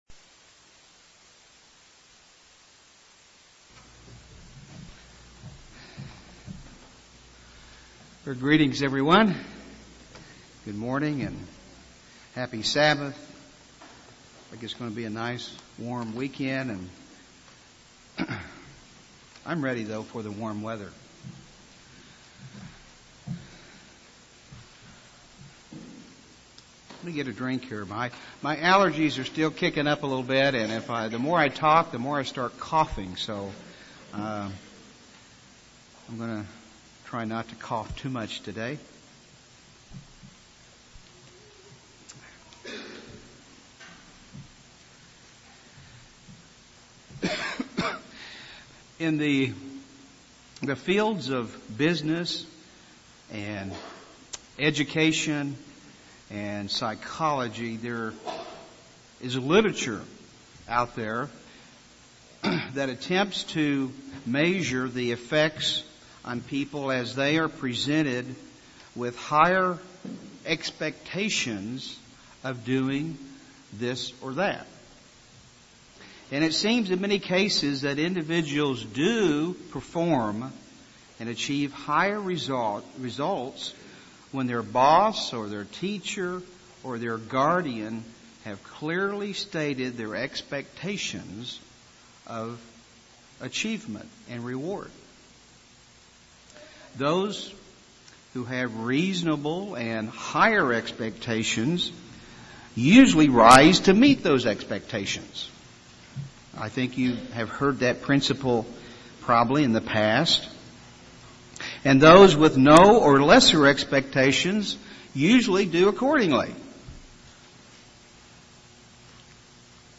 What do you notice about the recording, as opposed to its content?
Given in Tulsa, OK